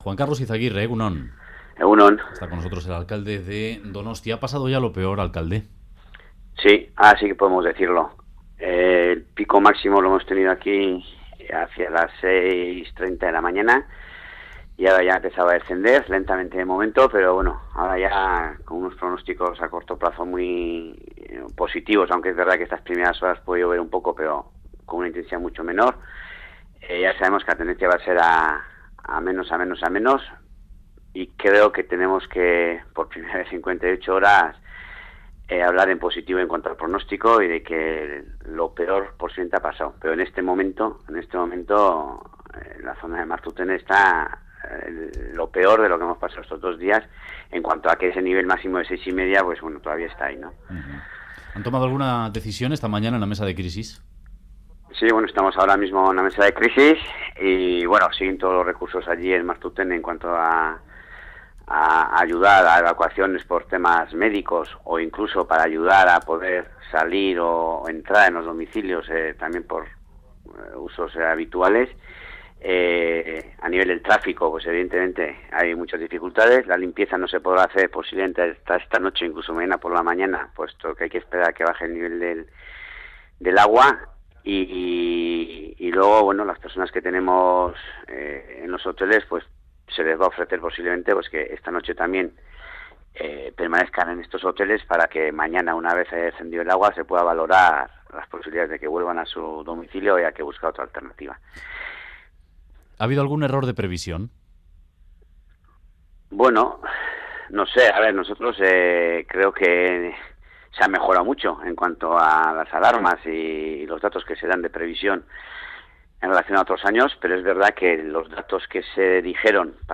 Radio Euskadi BOULEVARD 'Como en Txomin o Martutene no se puede estar ni en 2015 ni en 1970' Última actualización: 27/02/2015 10:38 (UTC+1) En entrevista al Boulevard de Radio Euskadi, el alcalde de Donostia, Juan Karlos Izagirre, ha lamentado las inundaciones que padecen los barrios de Txomin y Martutene, y ha dicho que así no se puede estar ni en 2015 ni en 1970. Ha recordado que está en marcha el plan que dentro de dos años acabará con esta situación, y sobre lo ocurrido en los últimos días ha apuntado que ha caído el triple de lluvia de lo que estaba previsto.